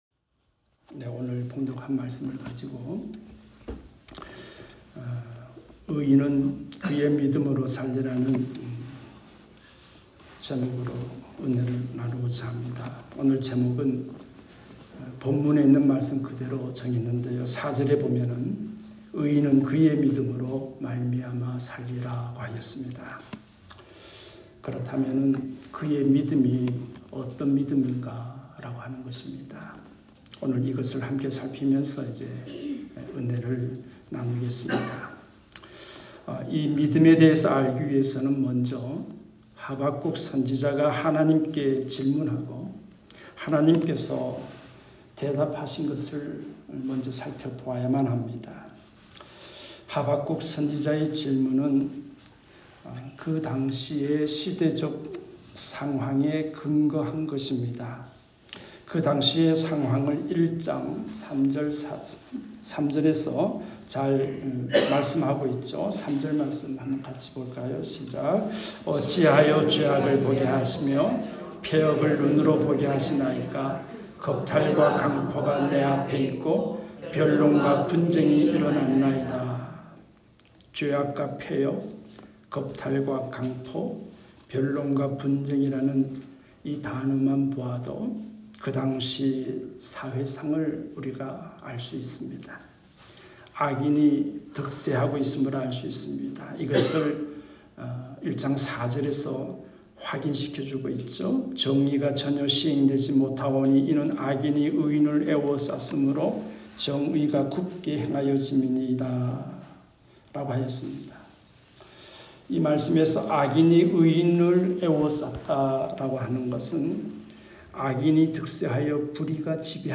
의인은 그의 믿음으로 살리라 ( 합2:1-4 ) 말씀